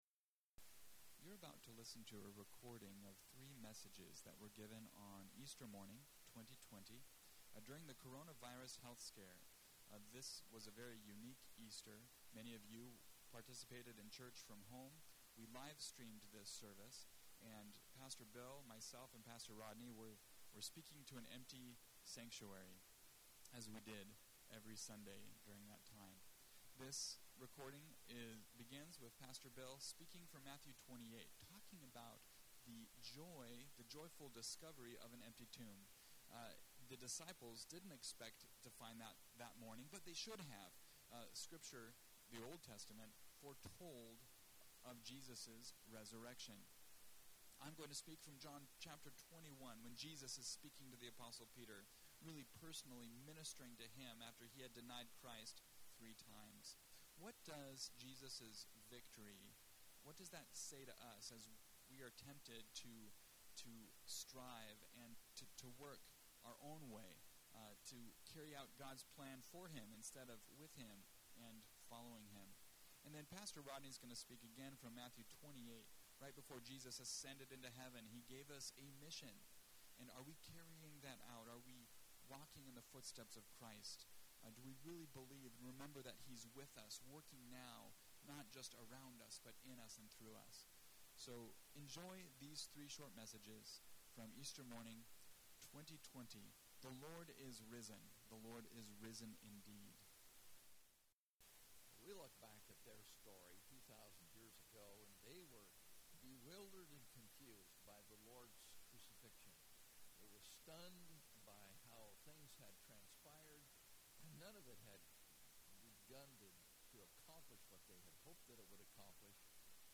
Online Church Service